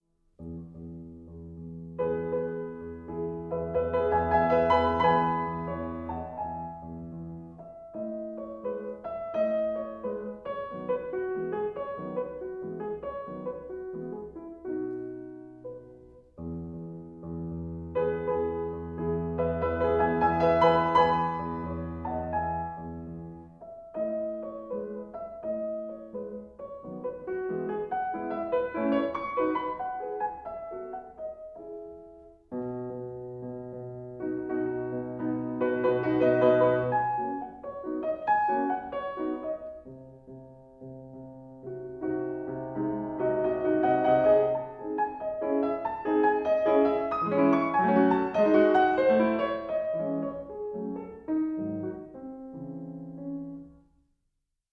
13 No 5 in E